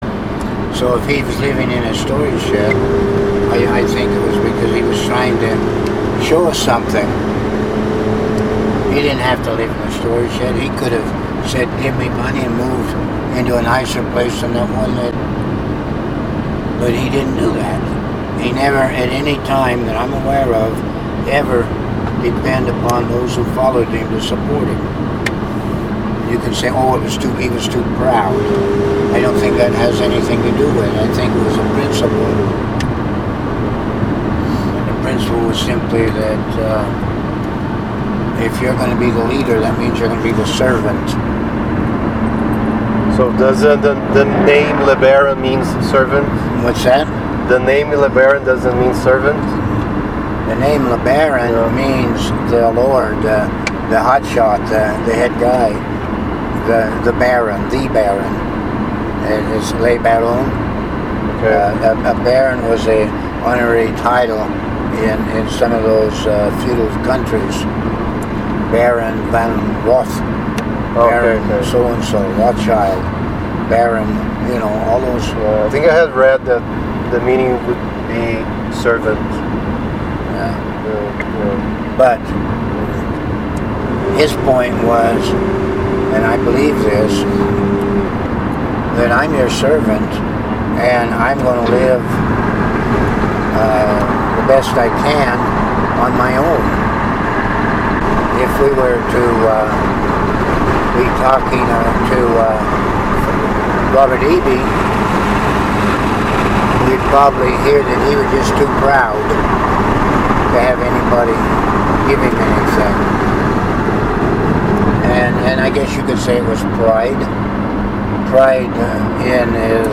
Posted byHolyOrder.OrgPosted inAudio, Interview, Memories, TeachingsTags:, , , , , , , , , , , ,